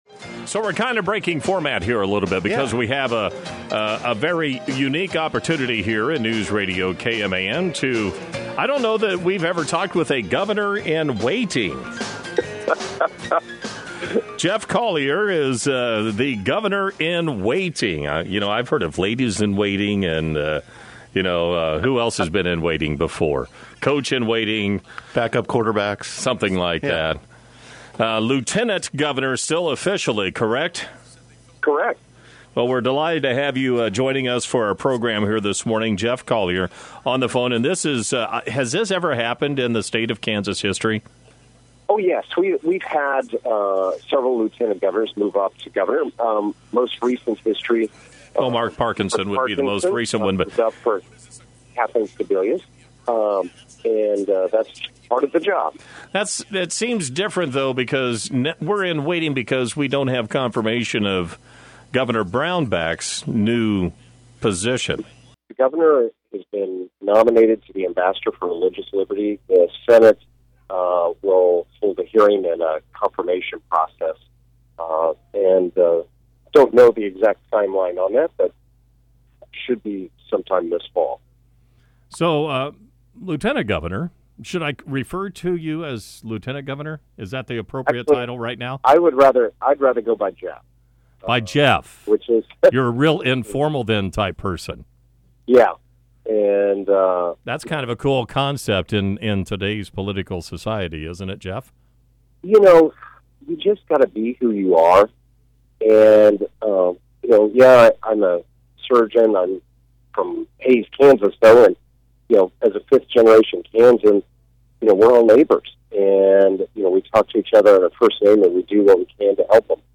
Colyer spoke with KMAN via telephone during Tuesday morning’s live newscast.
Colyer-full-interview.mp3